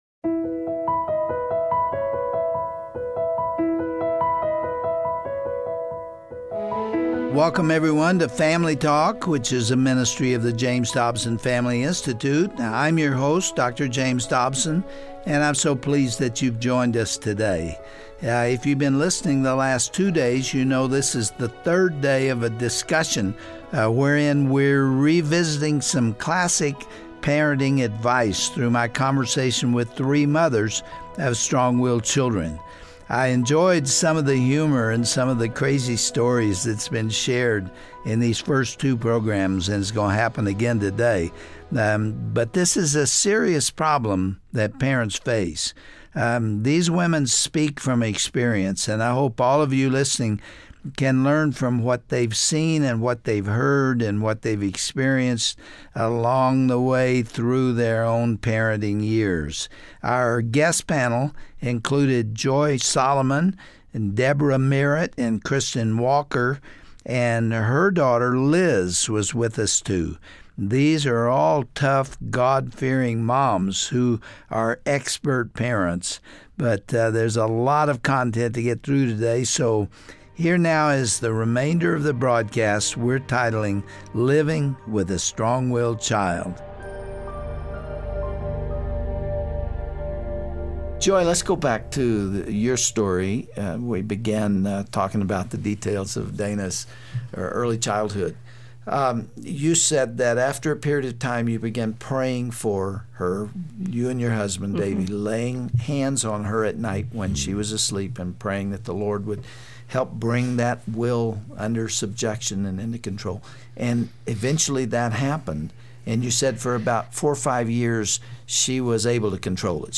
But the parents of strong-willed children grapple with that spiritual reality more dramatically. On today's edition of Family Talk, Dr. James Dobson visits for a third day with three mothers who fasted and prayed and disciplined until they saw victory.